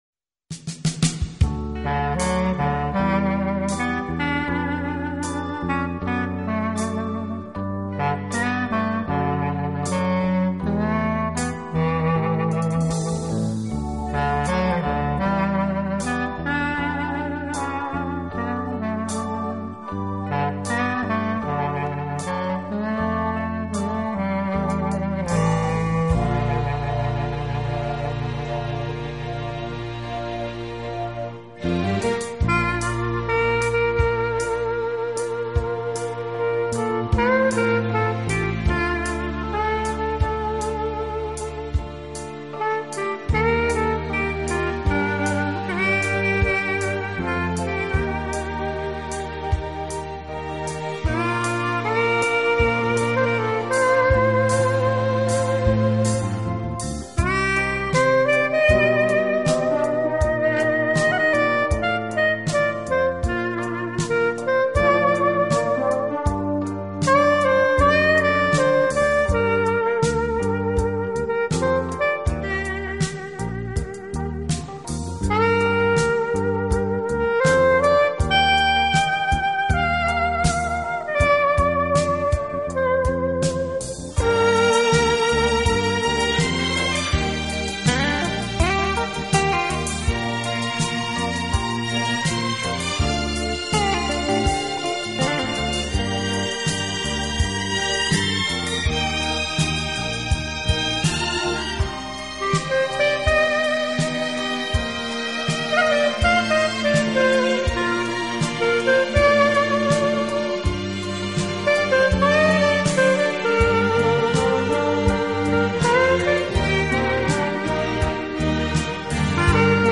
【黑管专辑】